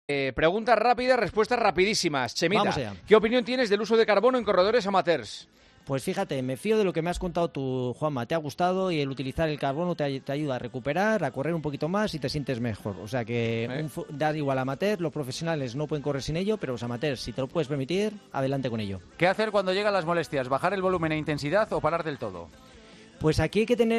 Juanma Castaño pregunta a Chema Martínez su opinión sobre el uso de zapatillas de carbono en corredores aficionados